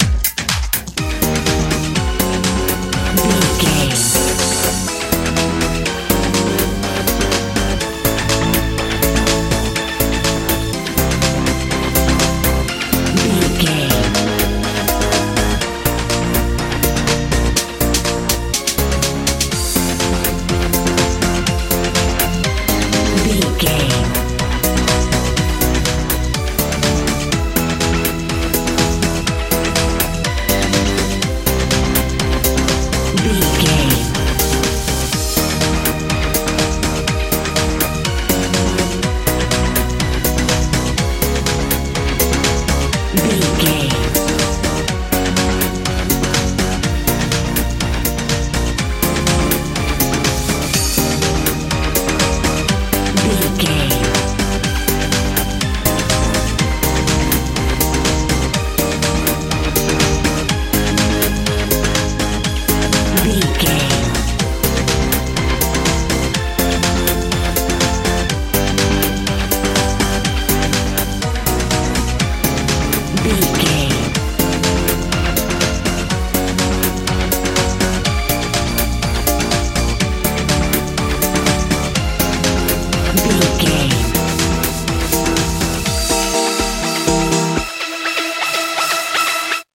modern dance
Ionian/Major
E♭
energetic
lively
bass guitar
synthesiser
drums
80s
90s
tension
intense
bouncy